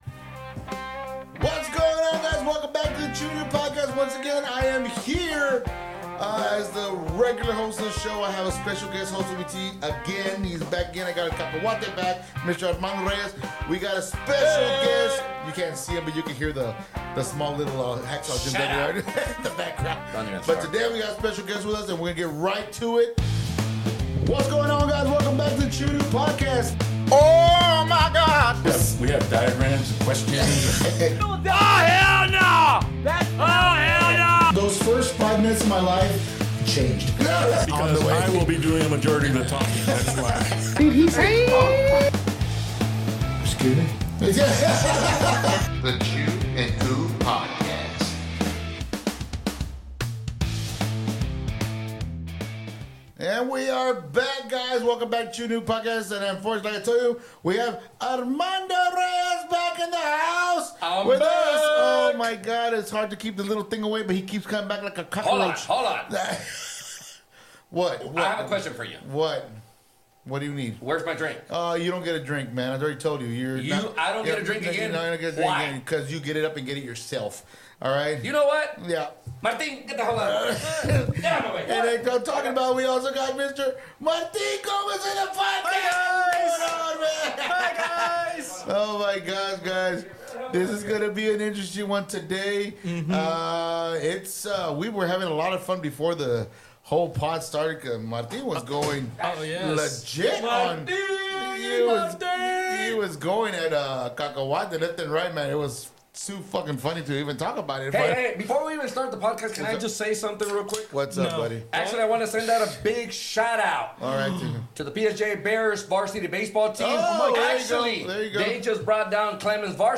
Join us for a thrilling and entertaining ride as we bring you exclusive interviews with local talent, businesses, artists, actors, and directors from the RGV 956.